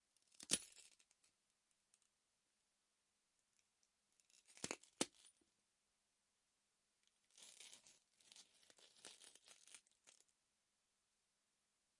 脚步声 " 踩在玻璃上
描述：破碎的玻璃上的步骤。在柏林的Funkhaus工作室录制。
Tag: footste PS 步骤 玻璃 步行 碎片 破碎 立体声